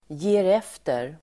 Uttal: [(²)je:r'ef:ter]